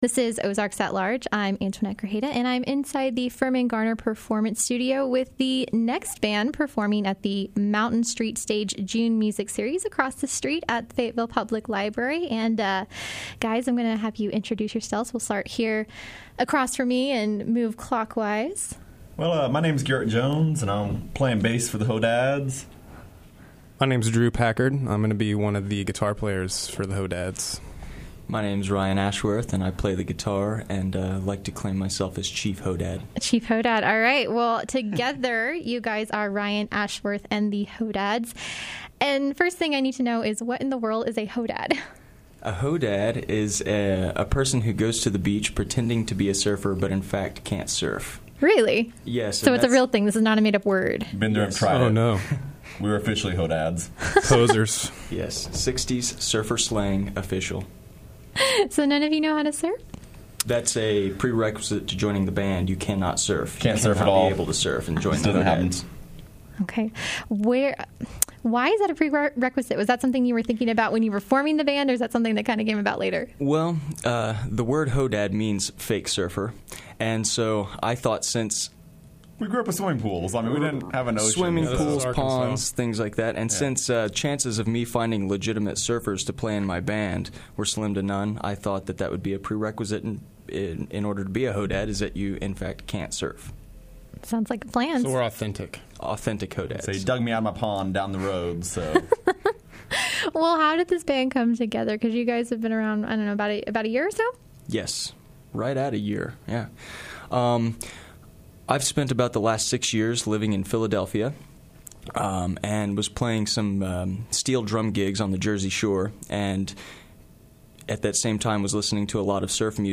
visit KUAF’s Firmin-Garner Performance Studio